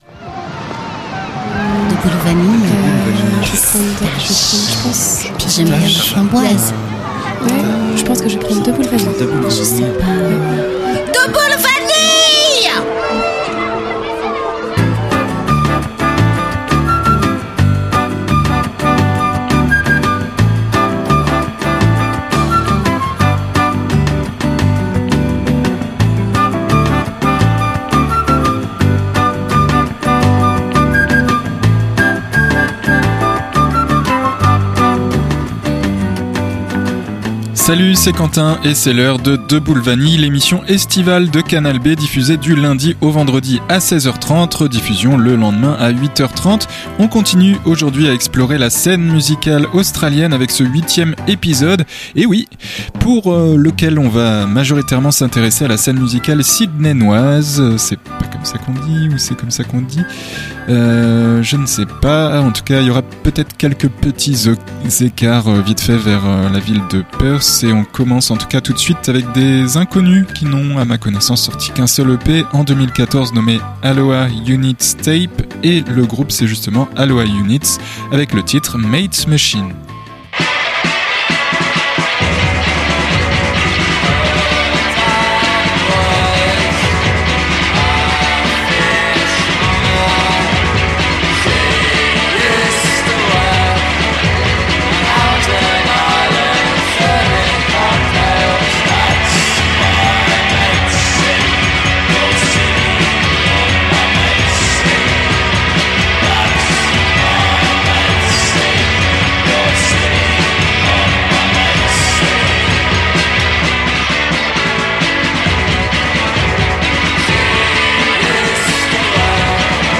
en matière de musique indé.